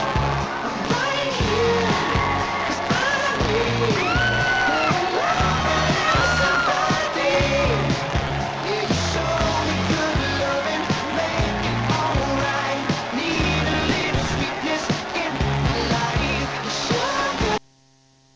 After changed to LJF mode, the noise level becomes smaller. we can hear the normal sounds, but it has a little background noise.
This is not the root cause, because the noise is continuous.